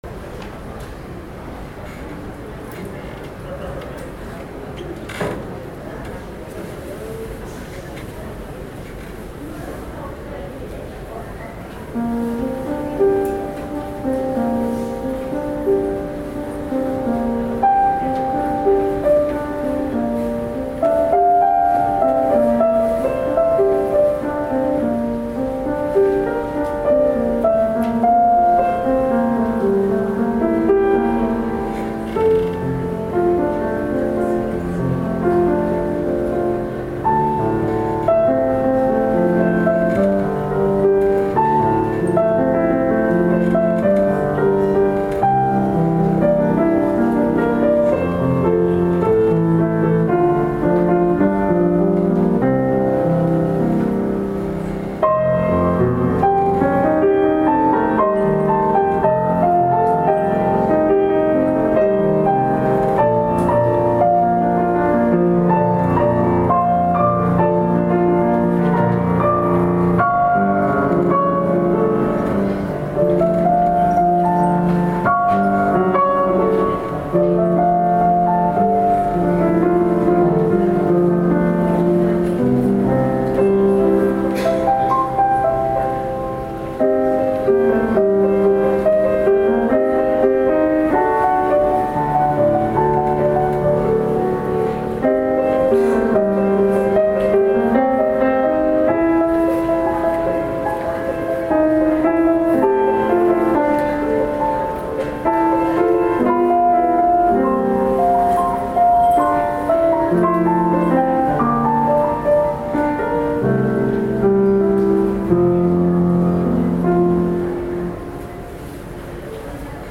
２０１４年９月１４日（日）　定禅寺ストリートジャズフェスティバルin仙台（電力ビルグリーンプラザ）
ピアノソロ
jazz-fes-2014-yume.mp3